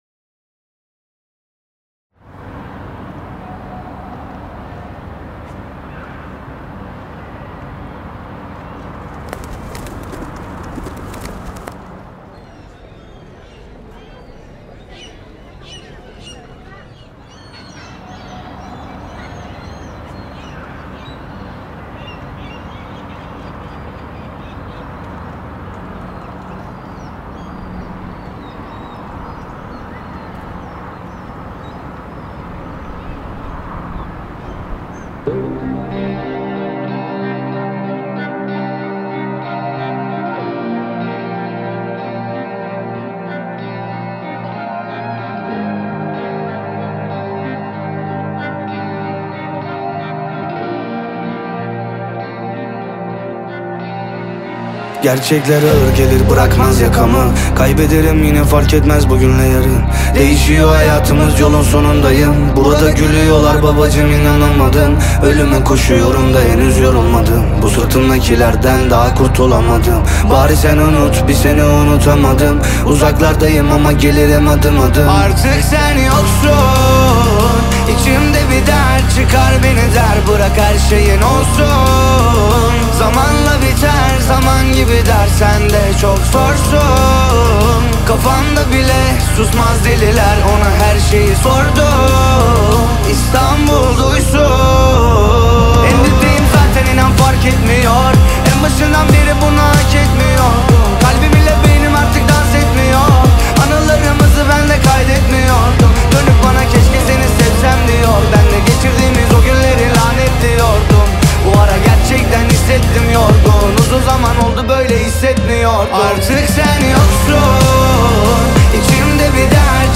smooth vocals and infectious melodies